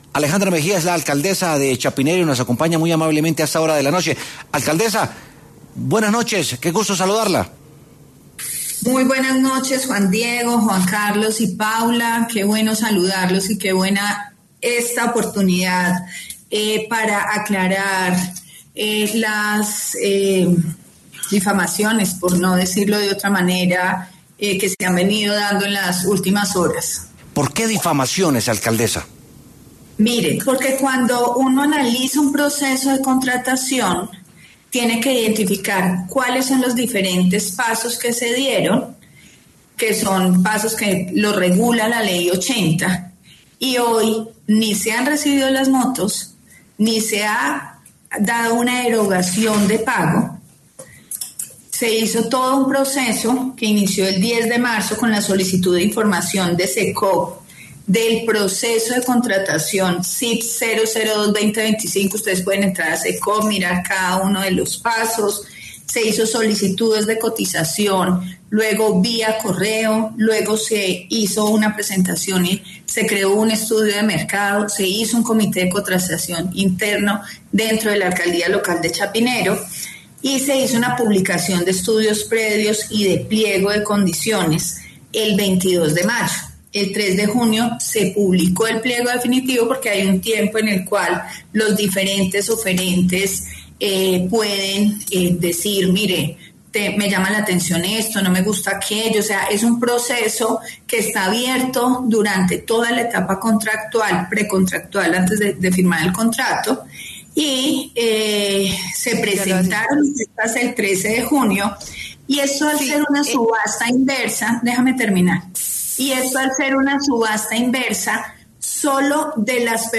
Este miércoles, 6 de agosto, Alejandra Mejía, alcaldesa de Chapinero, estuvo en W Sin Carreta y habló de la polémica de presunto sobrecosto en uno de los contratos de la localidad.